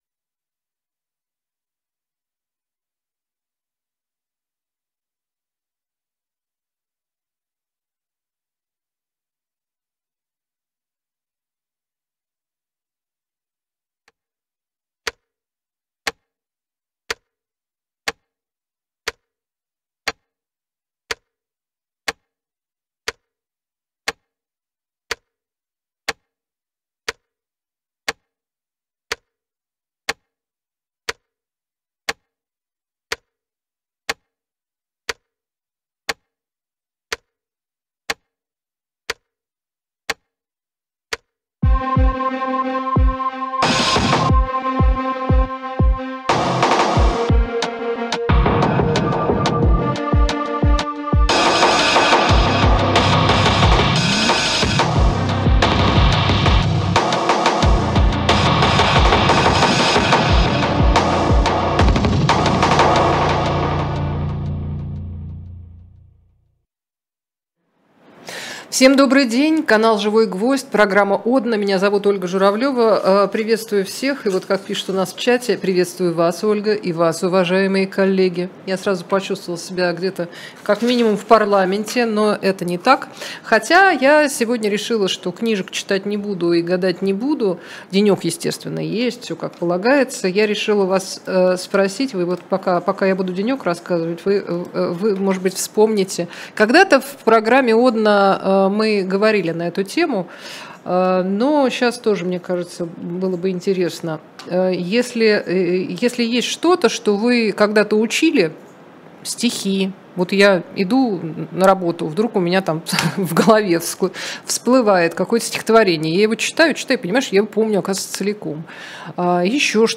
Ольга Журавлёва отвечает на ваши вопросы в прямом эфире.